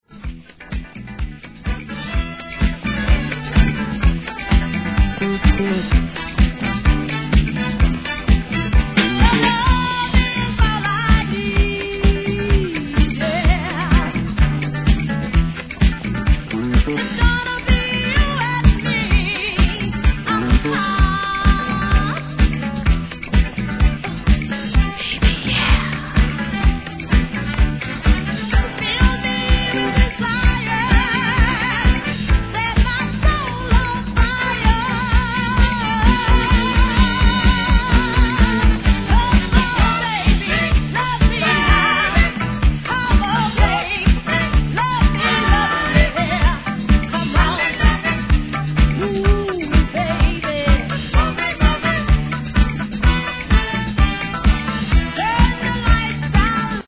FUNKY DISCO BOOGIE!!